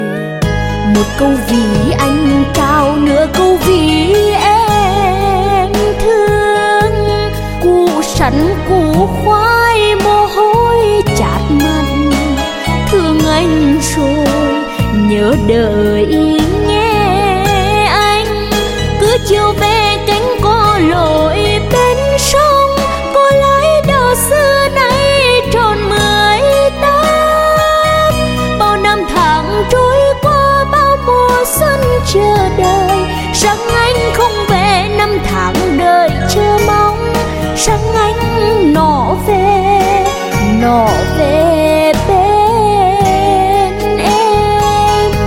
Nhạc Bolero